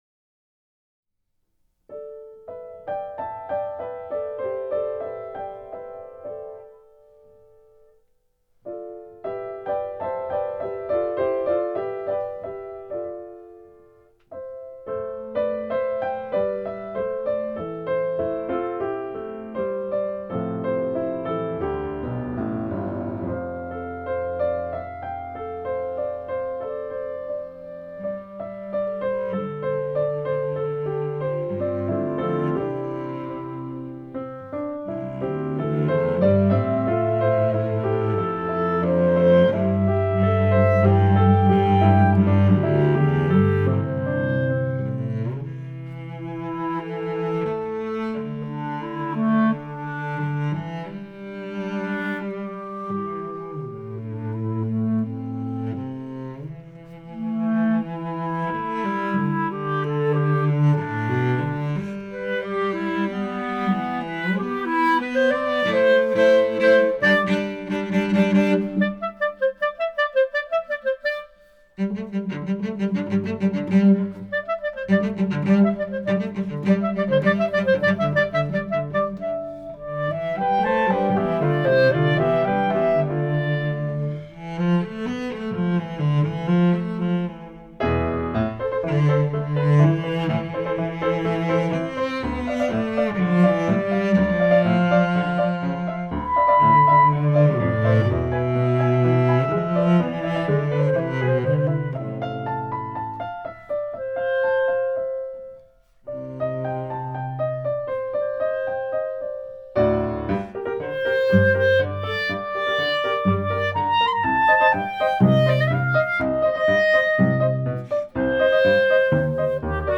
Clarinet, cello & piano